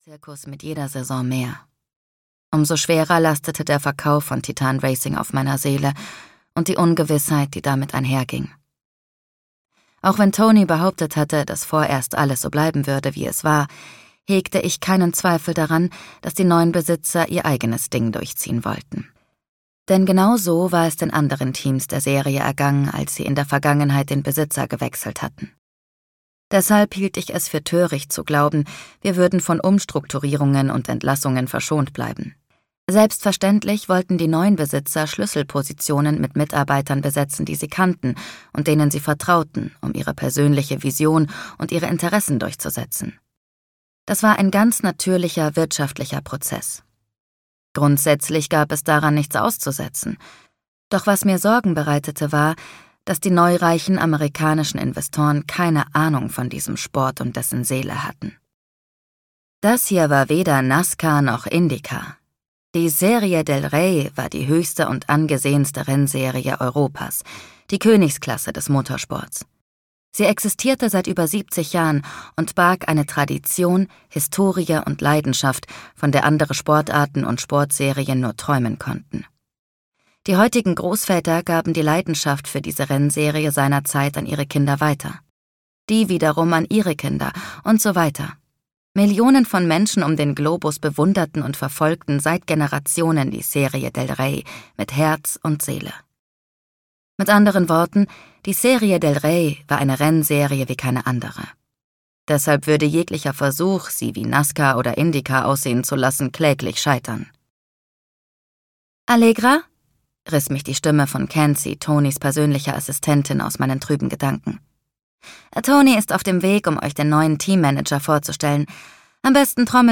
Crashing Hearts (DE) audiokniha
Ukázka z knihy